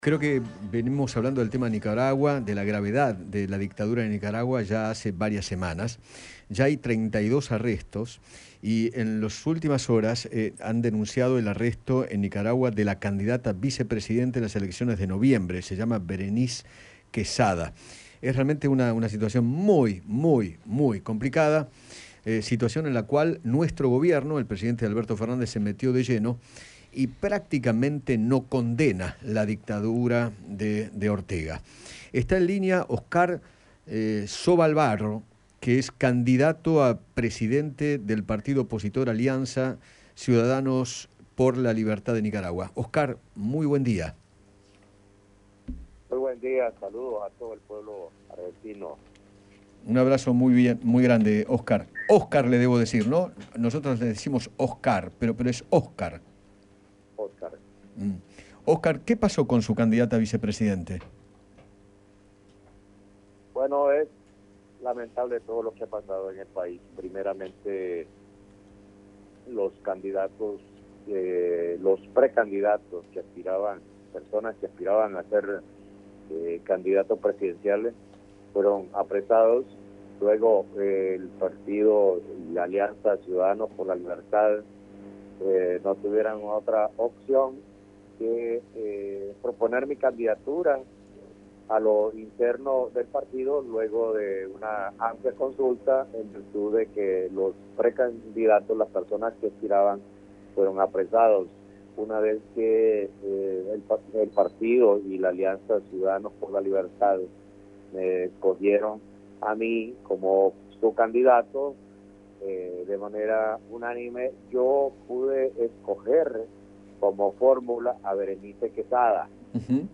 Óscar Sobalvarro, candidato presidencial en Nicaragua por el partido opositor Alianza Ciudadanos por la Libertad, conversó con Eduardo Feinmann sobre las elecciones presidenciales que se darán en noviembre y se refirió a la gravedad del régimen de Daniel Ortega.